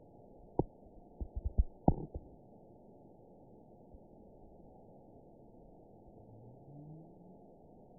event 920300 date 03/14/24 time 18:58:50 GMT (1 year, 1 month ago) score 8.15 location TSS-AB04 detected by nrw target species NRW annotations +NRW Spectrogram: Frequency (kHz) vs. Time (s) audio not available .wav